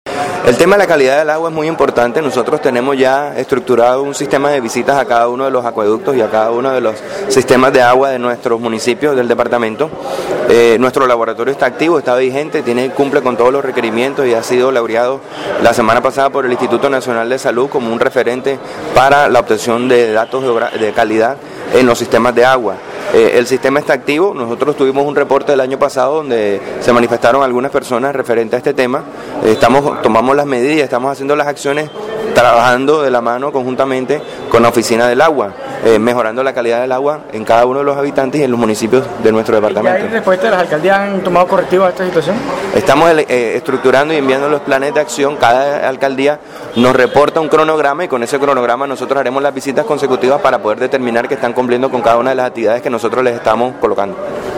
«Con el apoyo de la Secretaría de Agua Potable y con la disposición del Laboratorio Departamental, se vienen haciendo monitoreo periódico a los acueductos, no solamente de las cabeceras municipales, sino también a otras poblaciones para constatar que el agua que se suministra a las familia atlanticense sea de buena calidad», reiteró de De la Hoz en diálogo con Atlántico en Noticias.